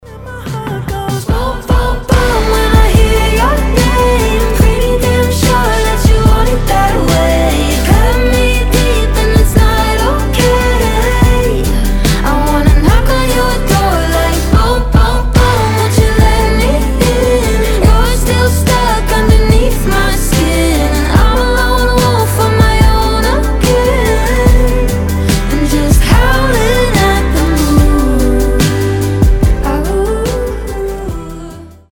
дуэт
красивый женский голос